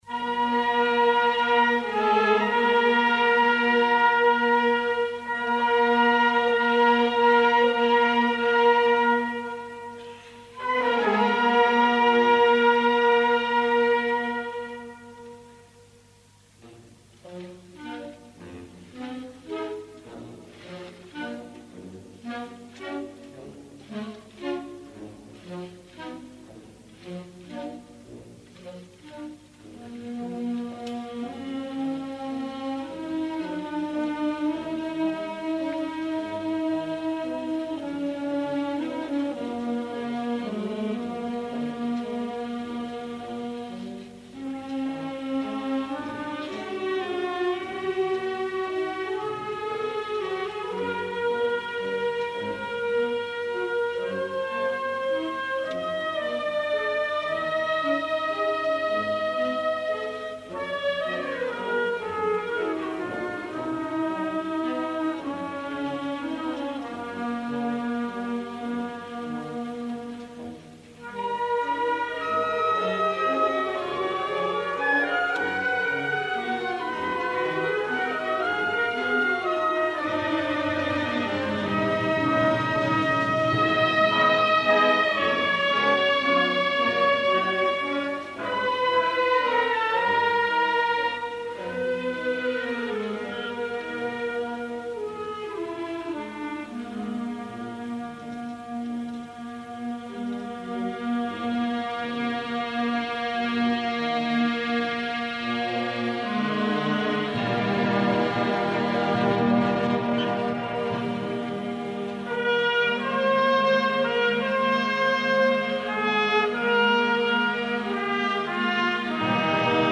Concert sa fira 1988. Esglesia parroquial de Porreres Nostra Senyora de la Consolació.